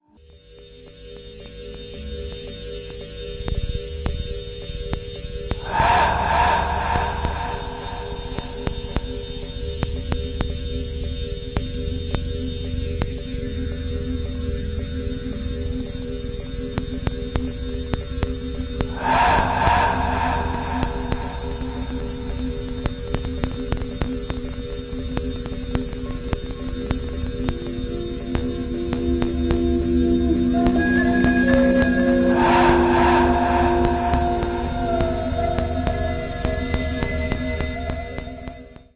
Flute
Synth modular